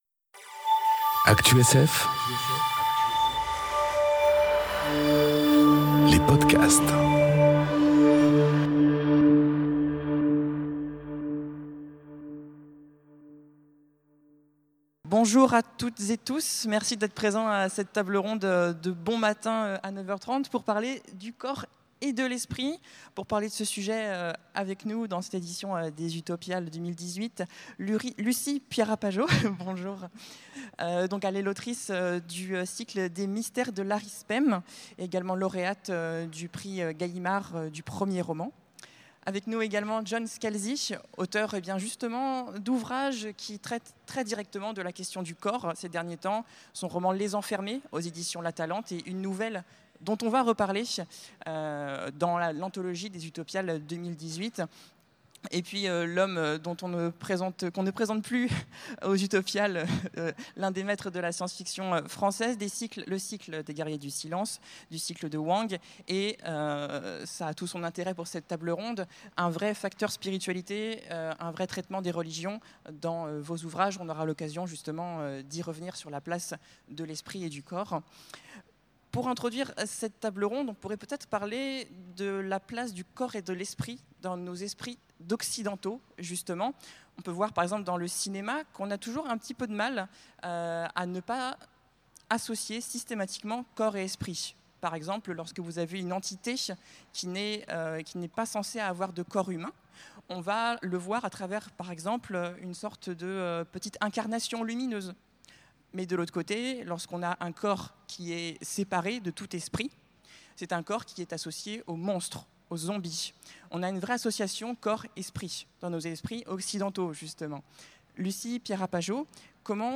Conférence Le corps et l'esprit enregistrée aux Utopiales 2018